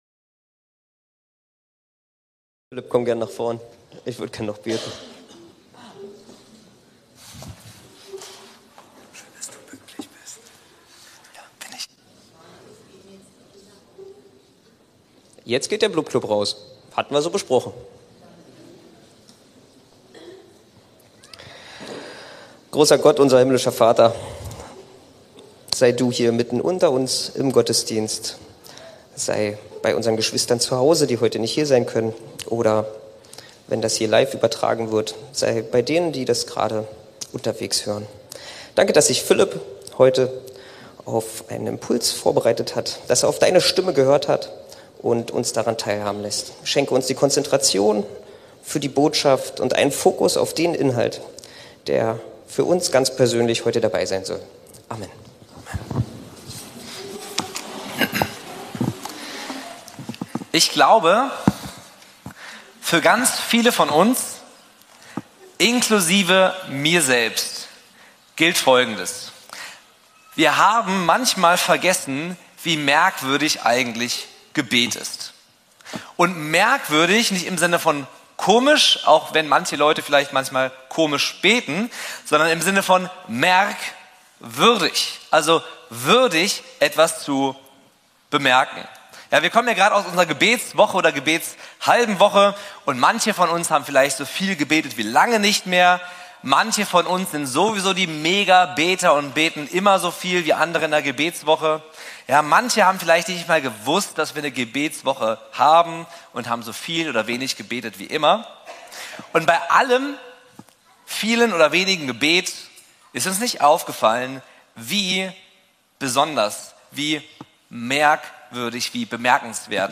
Impuls zum Gebetsgottesdienst